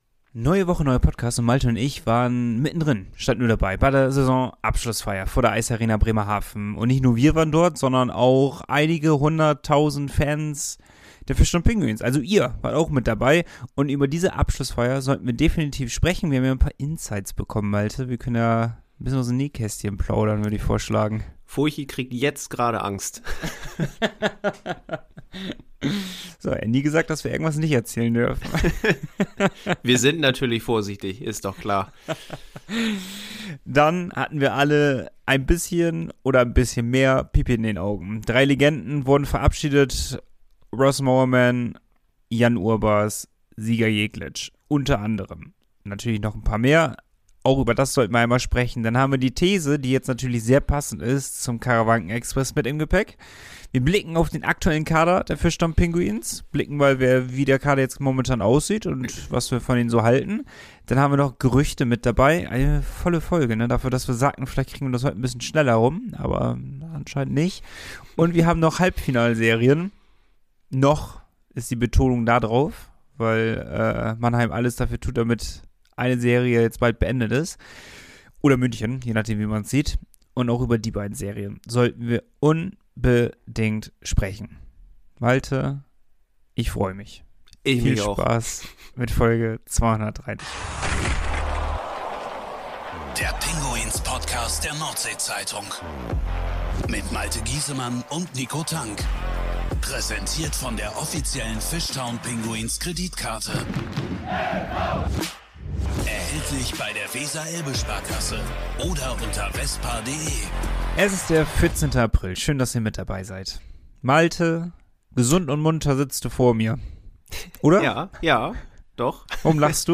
Pinguins-Podcast #273: Die Mannschaft ~ Pinguins-Podcast – Der Eishockey-Talk der NORDSEE-ZEITUNG Podcast